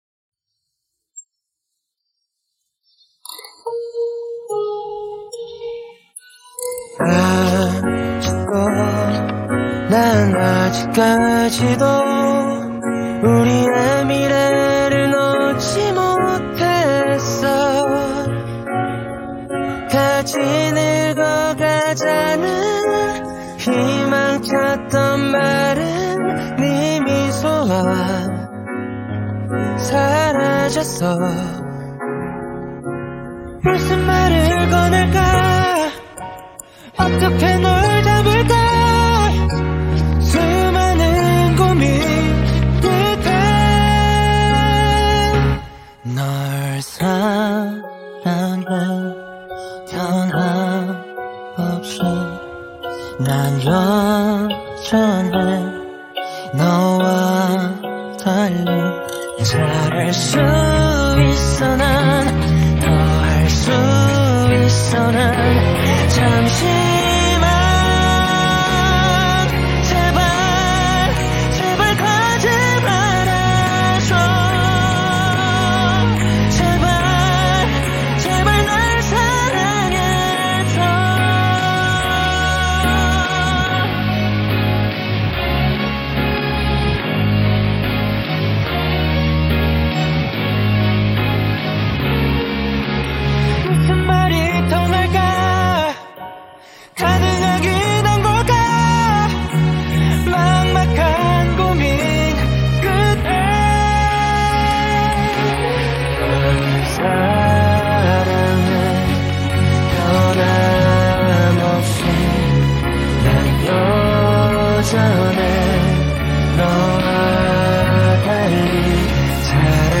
日韩歌曲